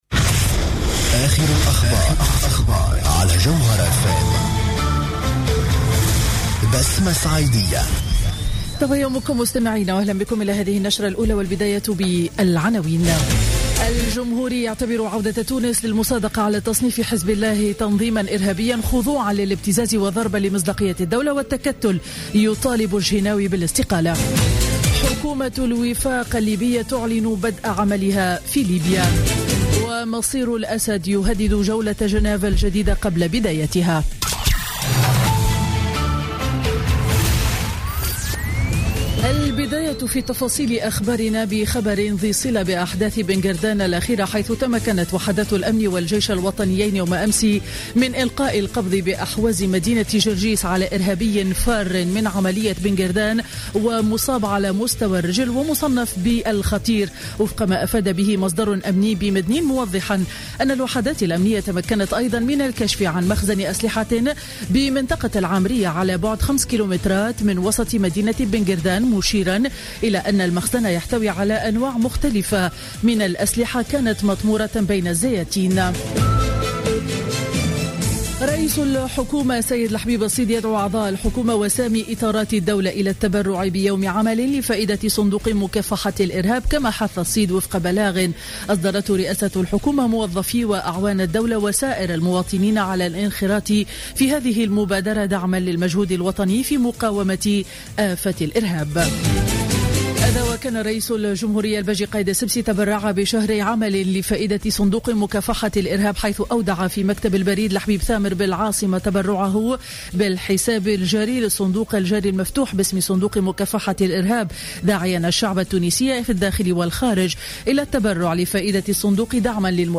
نشرة أخبار السابعة صباحا ليوم الأحد 13 مارس 2016